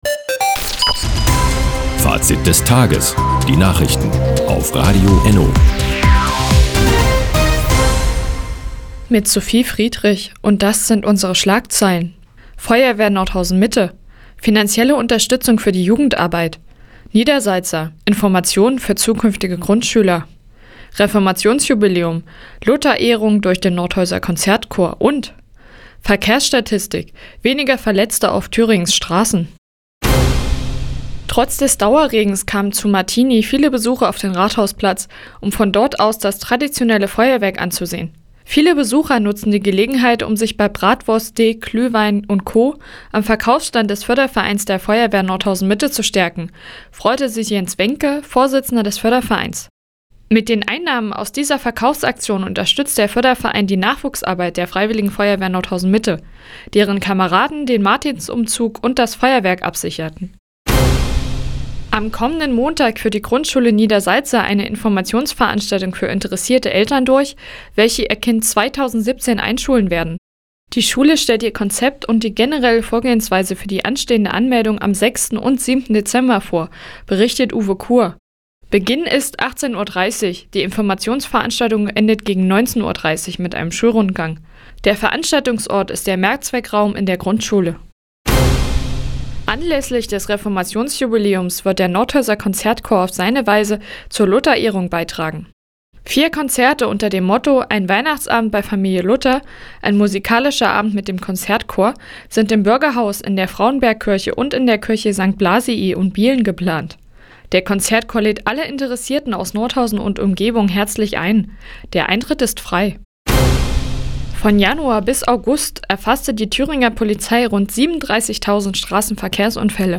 Di, 17:00 Uhr 15.11.2016 Neues von Radio ENNO Fazit des Tages Anzeige symplr (1) Seit Jahren kooperieren die Nordthüringer Online-Zeitungen und das Nordhäuser Bürgerradio ENNO. Die tägliche Nachrichtensendung ist jetzt hier zu hören.